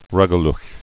(rŭgə-lə)